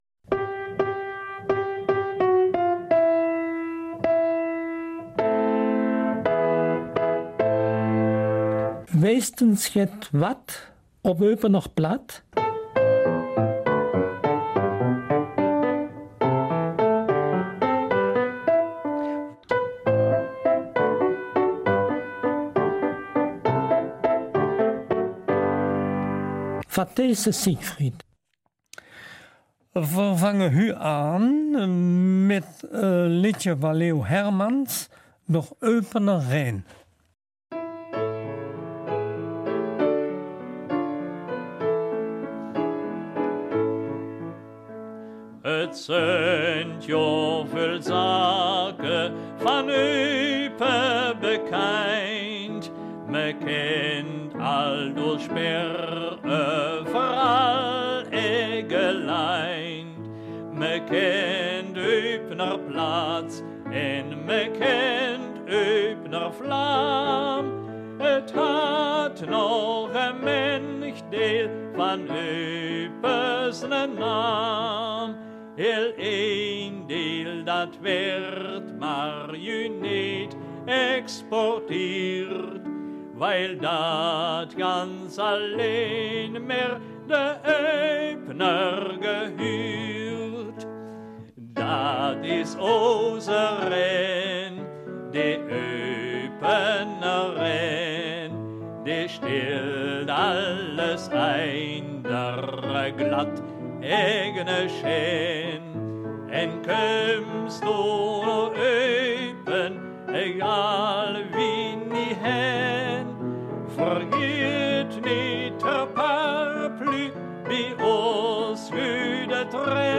Eupener Mundart - 13. November